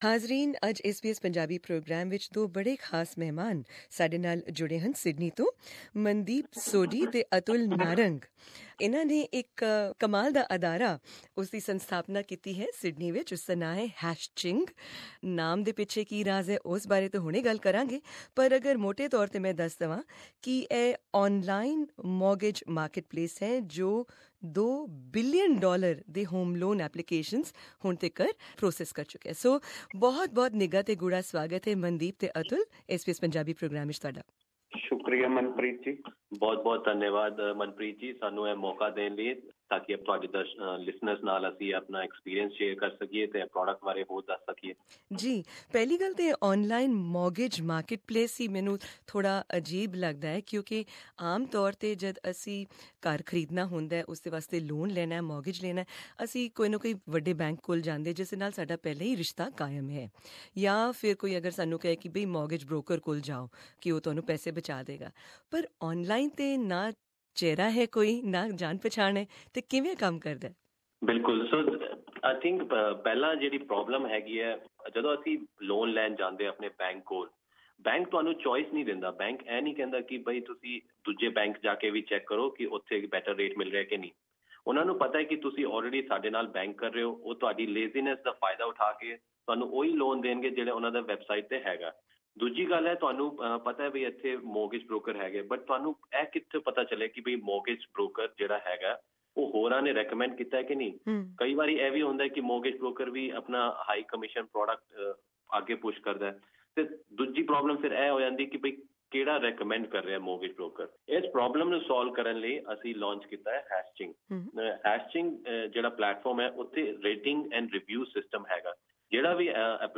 In this exclusive interview with SBS Punjabi